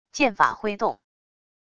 剑法挥动wav音频